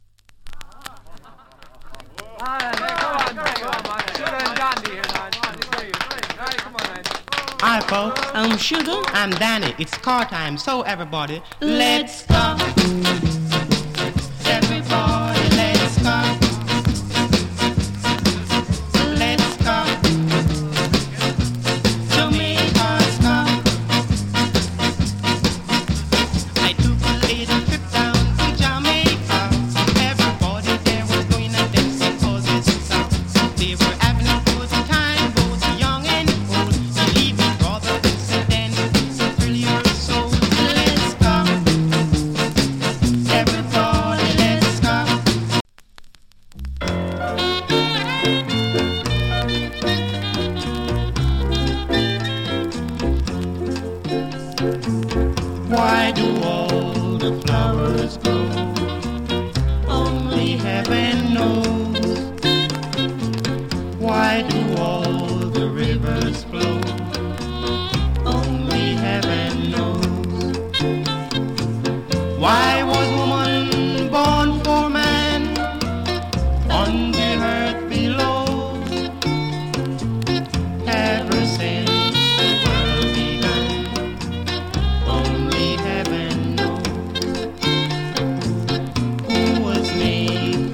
チリ、パチノイズ少々有り。